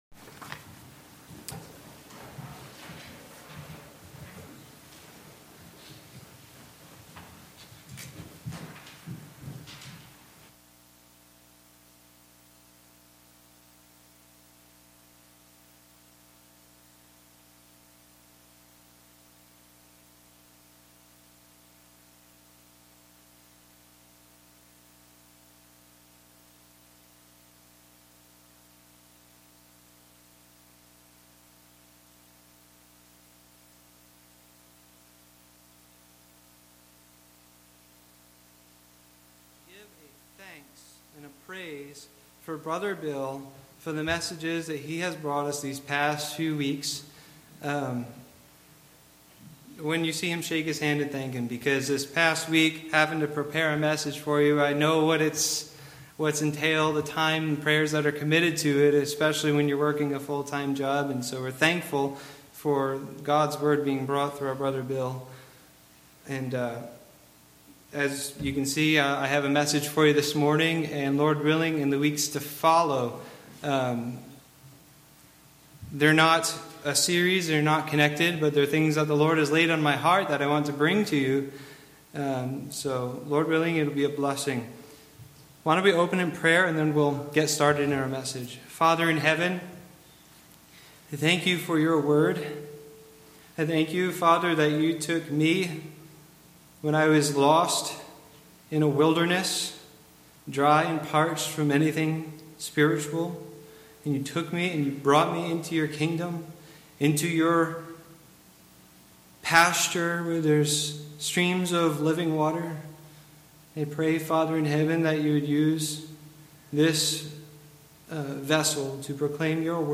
5-8 Service Type: Sunday Morning Worship Bible Text